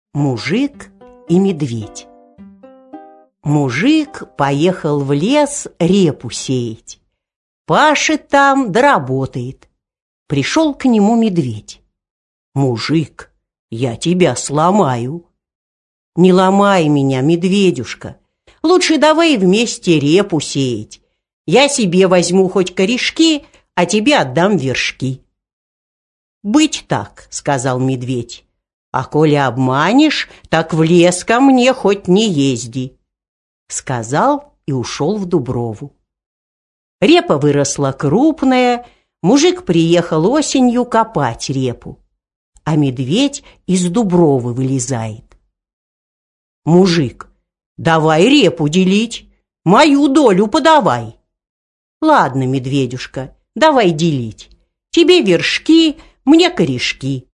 Аудиокнига Мужик и Медведь | Библиотека аудиокниг
Aудиокнига Мужик и Медведь Автор Группа авторов.